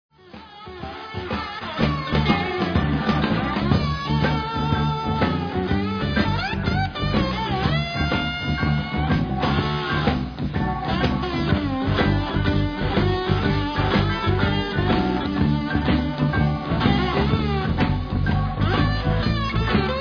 Southern (jižanský) rock